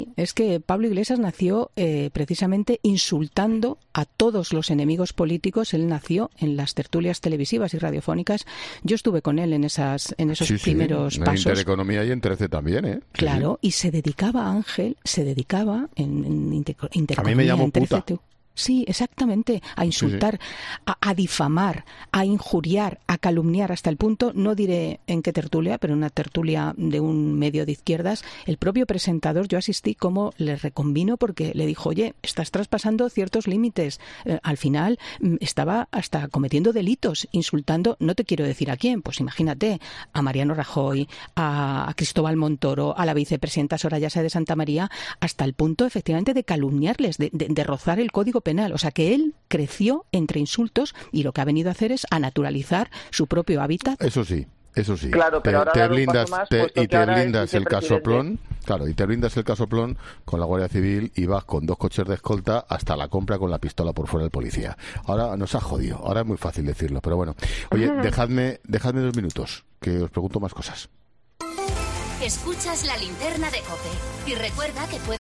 El momento al que hacía referencia Expósito era una tertulia vivida en ‘El Cascabel’ de TRECE en el que, en 2015, Iglesias debatía con él por la educación pública.